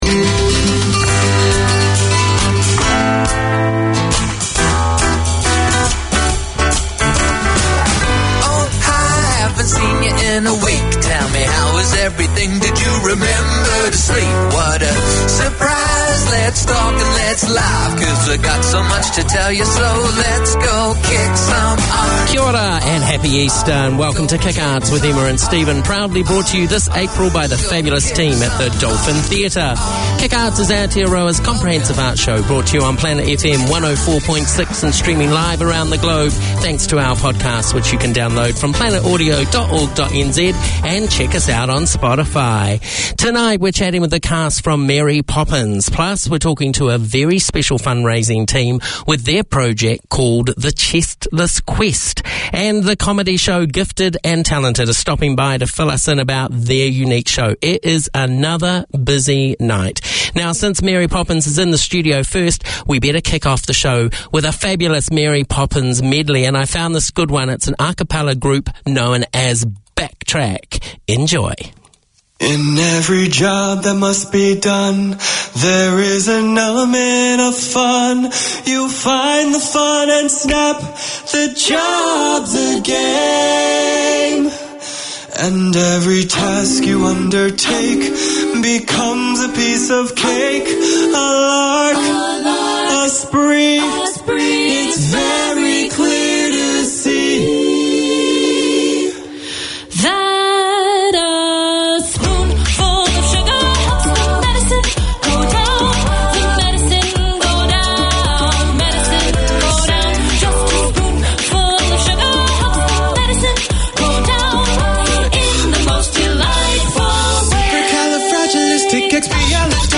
Three serious and not-so-serious librarians from Auckland Libraries explore the world of books with guest authors, recommended reads, news and reviews, poetry and gems from the Central Library basement and Special Collections. There are Book Club briefs with tips and connections.